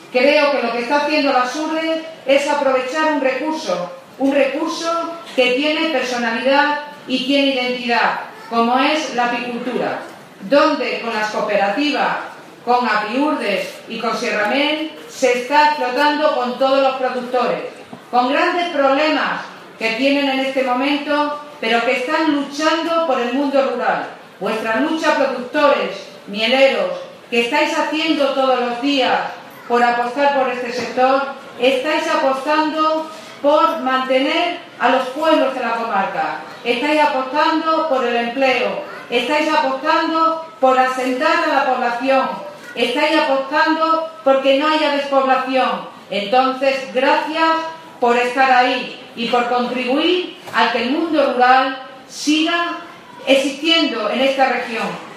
La presidenta de la Diputación, en la inauguración de la Feria de la Apicultura, ha señalado que la apicultura y el turismo son dos sectores que evitarán el despoblamiento de la comarca.
CORTES DE VOZ